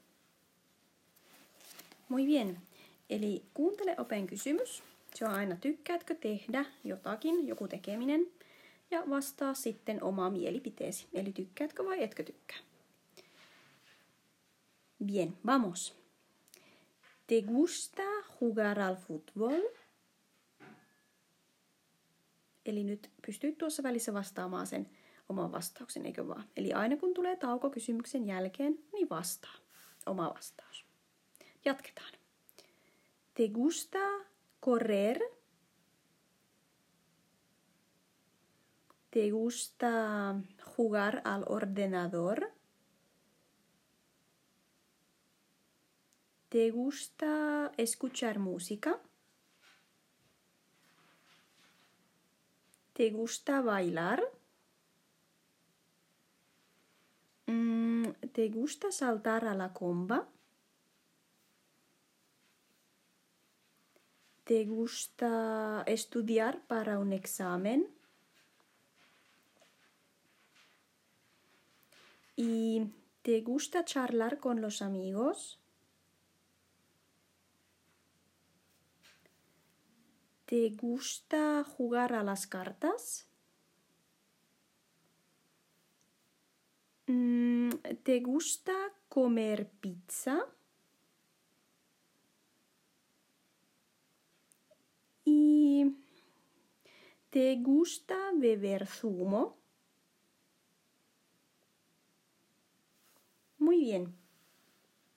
Open suomennos tekstikirjan s. 27 texto B:stä: